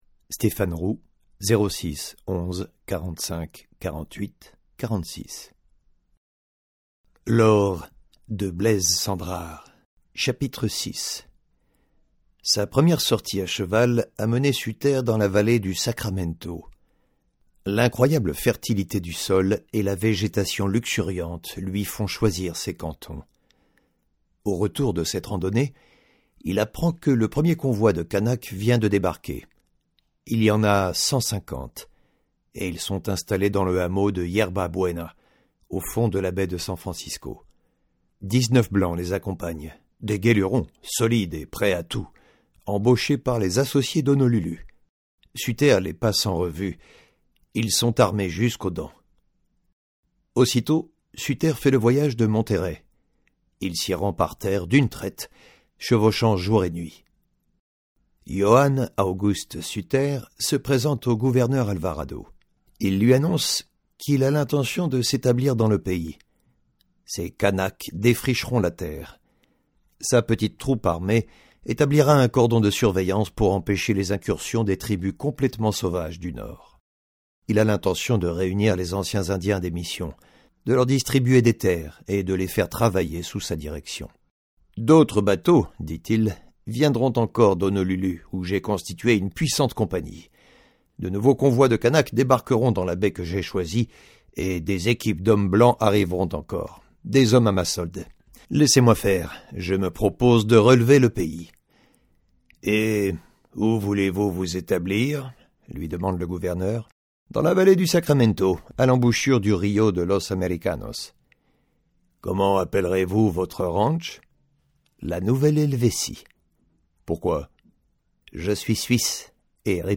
Démo Livres audio